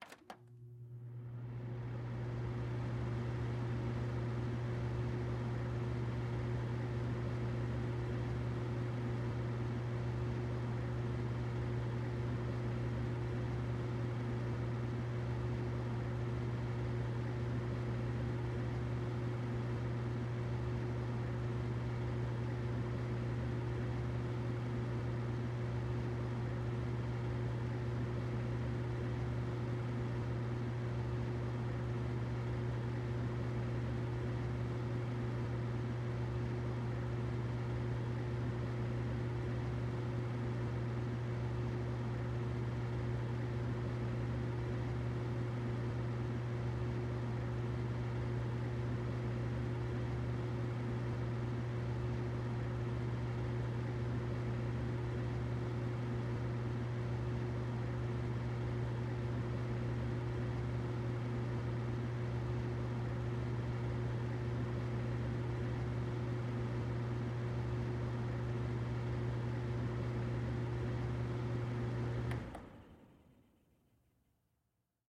Звуки увлажнителя воздуха
Включили увлажнитель и выключили